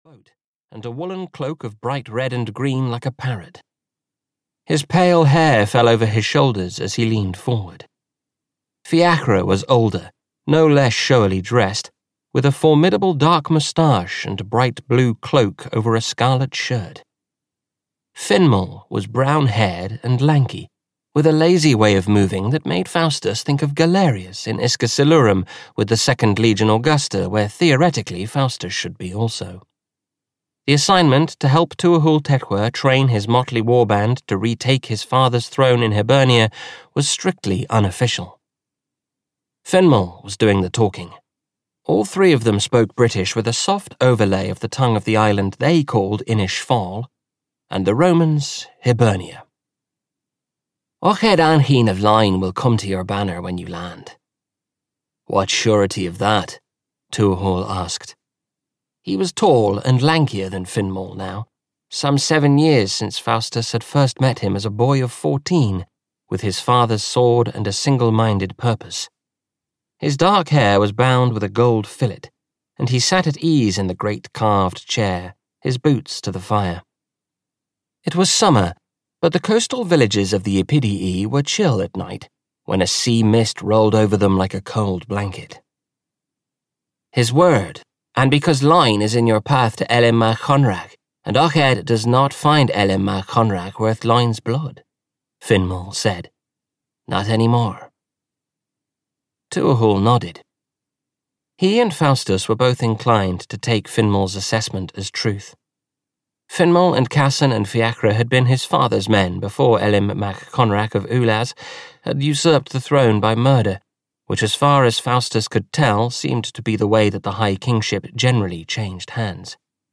Empire's Edge (EN) audiokniha
Ukázka z knihy